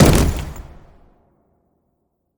anonTheaterFall.ogg